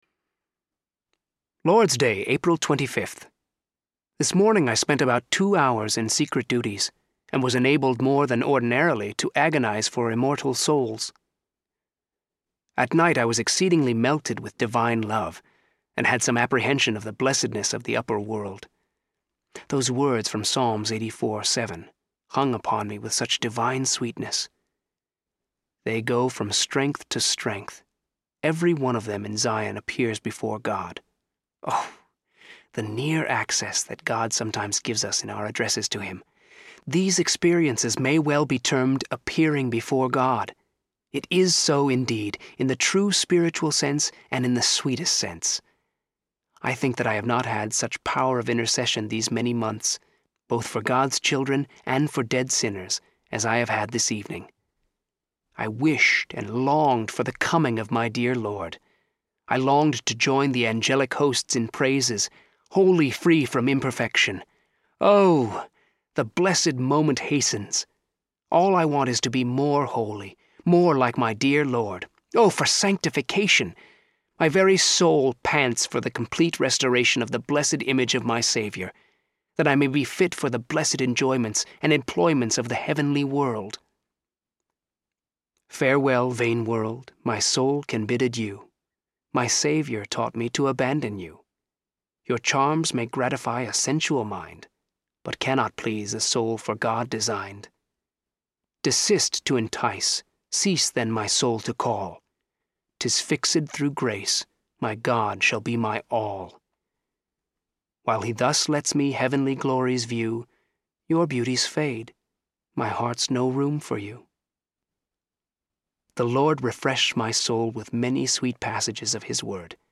The Life of David Brainerd Audiobook
Narrator
10 Hrs. – Unabridged